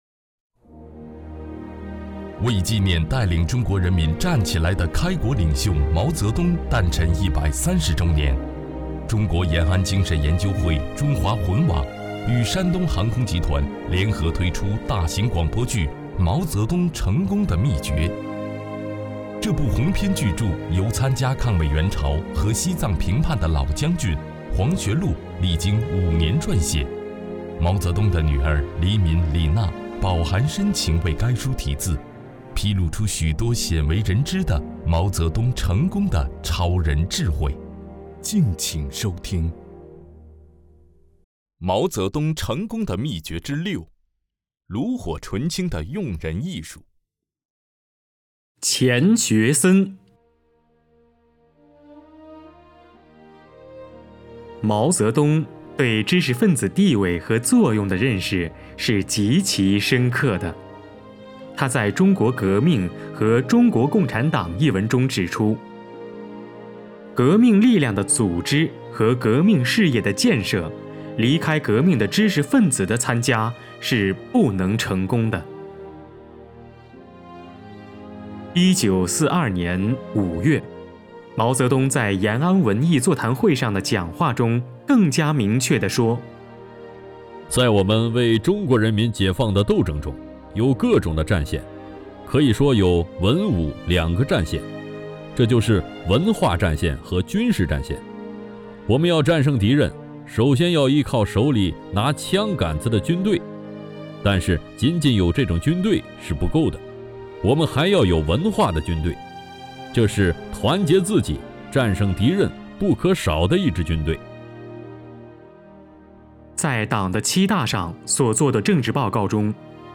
为纪念带领中国人民站起来的开国领袖毛泽东诞辰130周年，中国延安精神研究会《中华魂》网与山东航空集团联合推出大型广播剧《毛泽东成功的秘诀》。